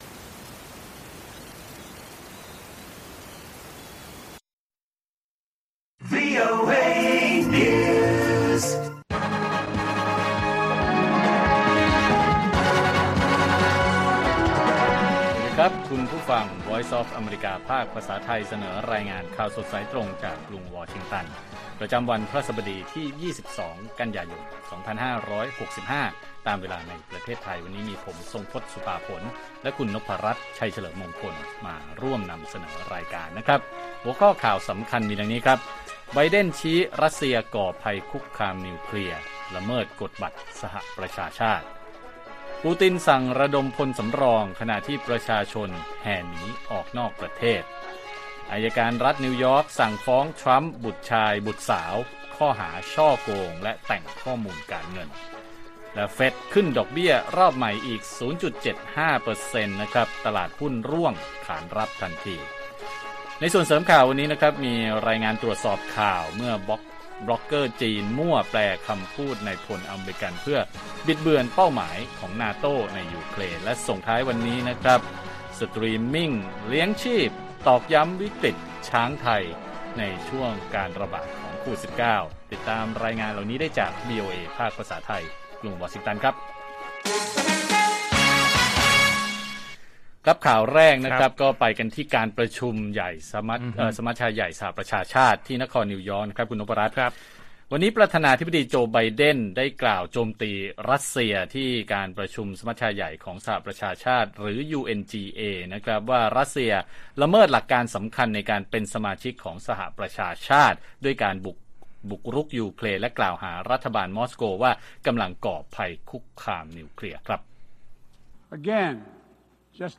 ข่าวสดสายตรงจากวีโอเอไทย 8:30–9:00 น. วันที่ 22 ก.ย. 65